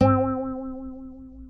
Guitar - Dingo.wav